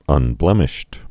(ŭn-blĕmĭsht)